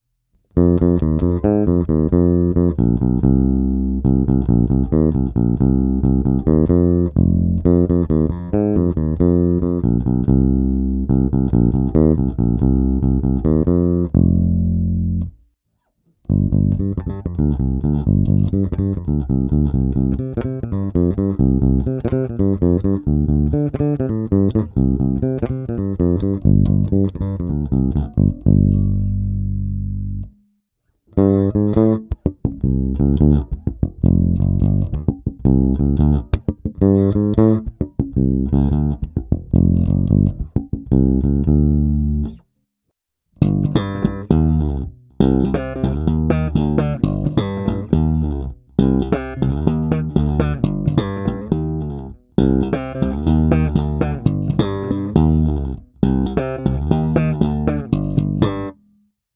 Pevný, vrčivý, průrazný.
Následující nahrávky jsem provedl rovnou do zvukové karty a jen normalizoval, jinak ponechal bez jakéhokoli postprocesingu.